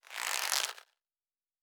Weapon UI 06.wav